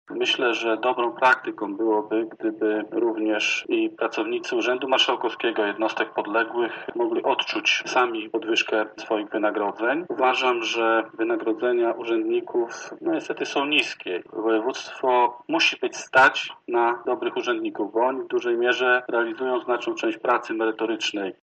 Wynagrodzenie samorządów było dotychczas zbyt niskie i ta decyzja jest potrzebna – mówi radny Koalicji Obywatelskiej Krzysztof Bojarski: